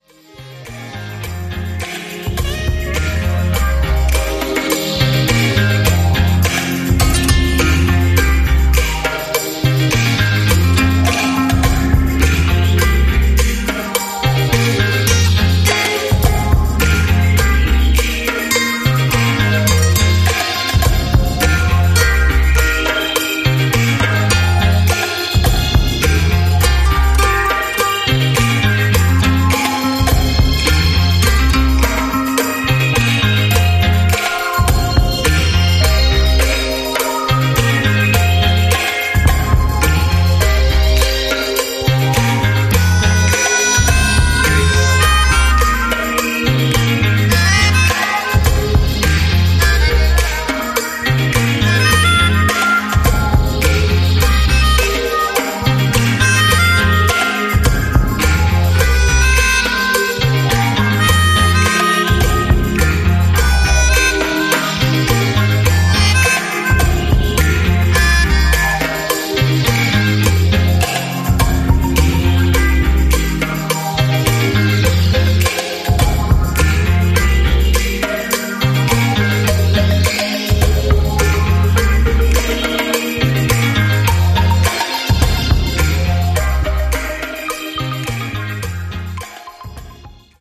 Really simple, minimal disco